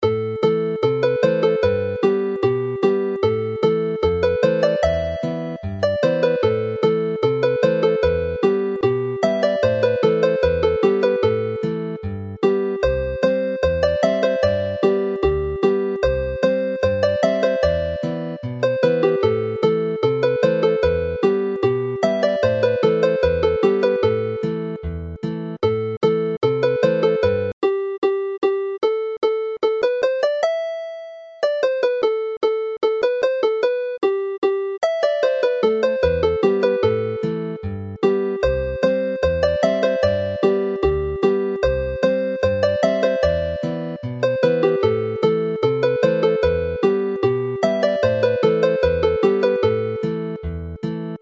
Two alternative tunes which are less musically sophisticated and easier to play, Aberllonydd and Aberawelon have been included here; both are simple and bring in a lighter feel to the dance before it returns to its formal musical base.